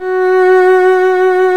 Index of /90_sSampleCDs/Roland L-CD702/VOL-1/STR_Violin 1 vb/STR_Vln1 % marc
STR VLN MT0A.wav